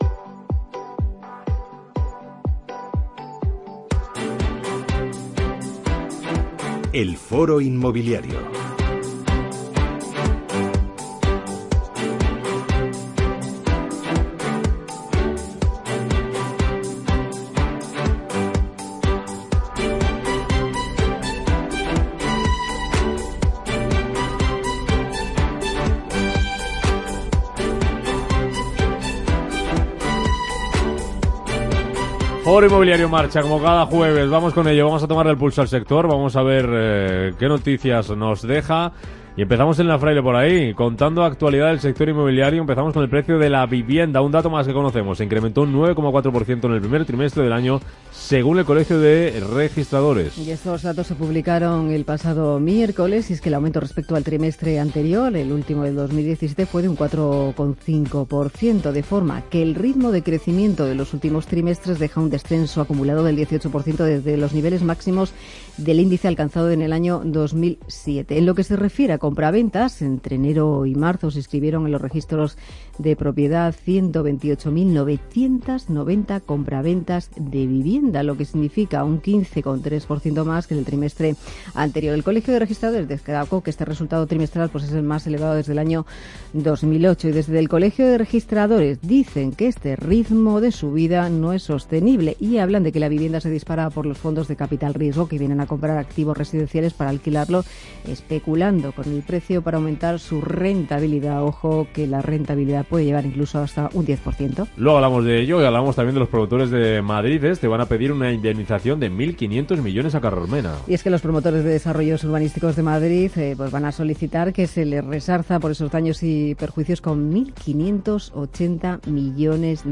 Todos estos temas y alguno más los puedes oír en el programa Foro Inmobiliario de Intereconomía Radio del pasado 17 de mayo de 2018.